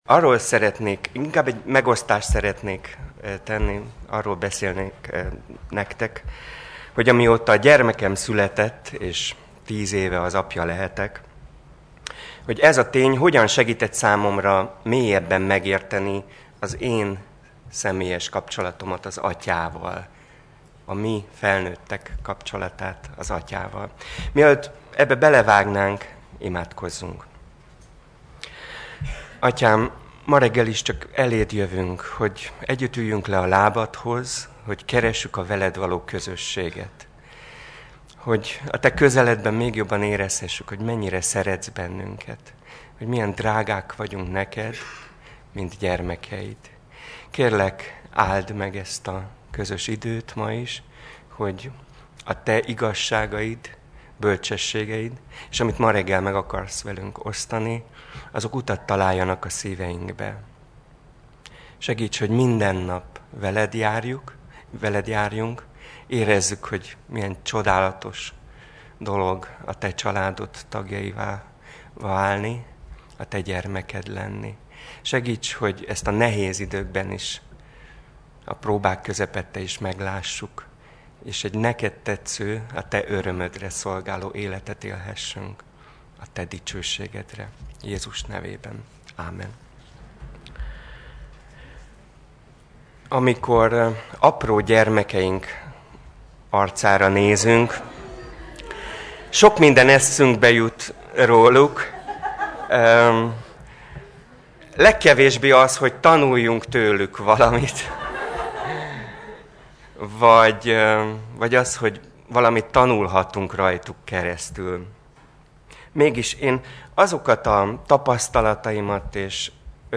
Sorozat: Vajta Konferencia 2010 Alkalom: Konferencia